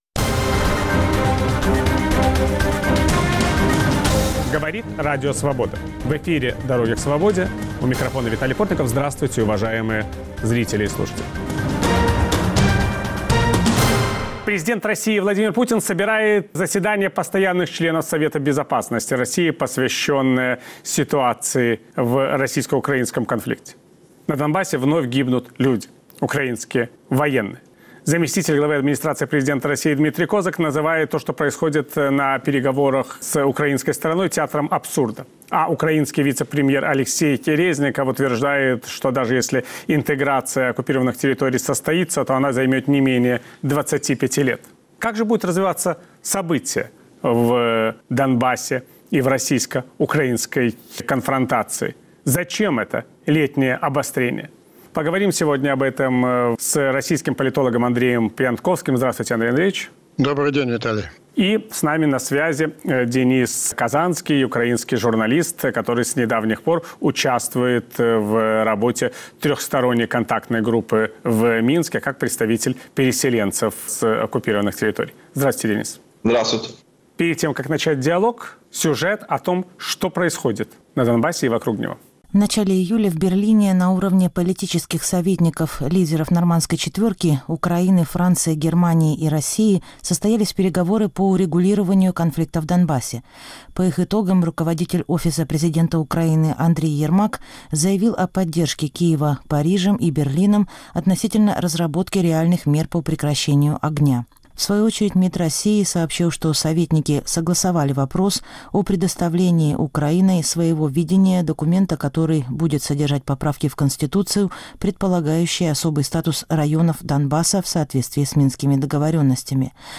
Понимают ли в Москве и Киеве цели друг друга обсуждая ситуацию в Донбассе? Виталий Портников беседует с политологом Андреем Пионтковским и журналистом